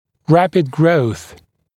[‘ræpɪd grəuθ][‘рэпид гроус]быстрый рост